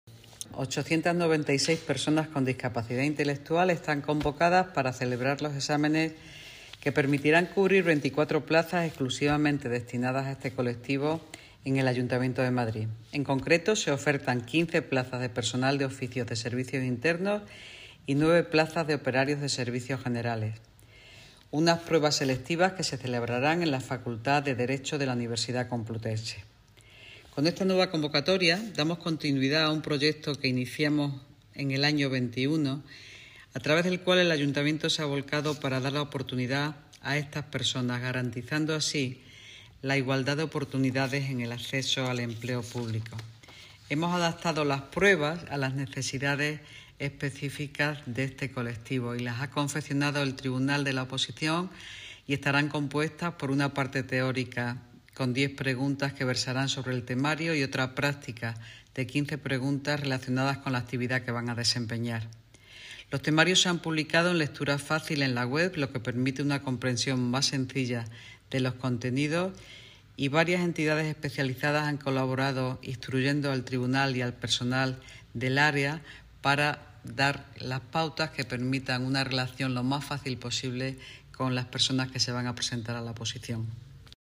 Nueva ventana:Declaraciones de la delegada de Economía, Innovación y Hacienda, Engracia Hidalgo